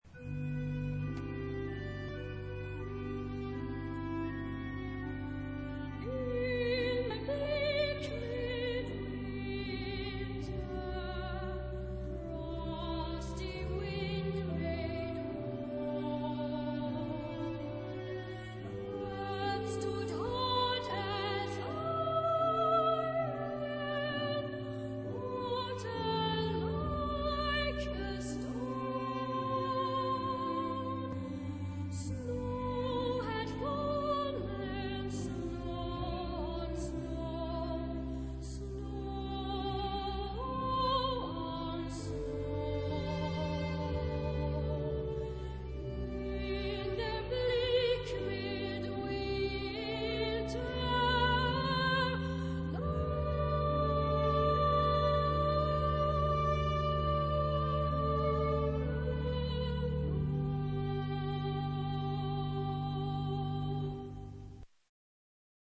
Carol
Genre-Stil-Form: Weihnachtslied ; geistlich ; Anthem
Charakter des Stückes: zart ; mäßig ; ruhig
Chorgattung: SATB  (4 gemischter Chor Stimmen )
Solisten: Soprano (1) / Tenor (1)  (2 Solist(en))
Instrumente: Orgel (1) oder Klavier (1)
Tonart(en): G-Dur